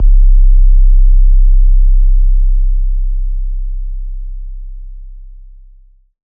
808 METRO BASS.wav